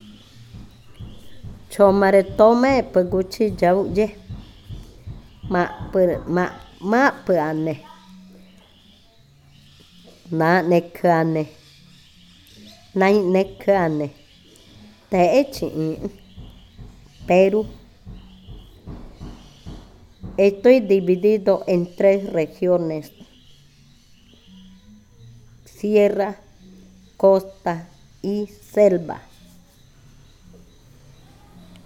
Adivinanza 20. Perú
Cushillococha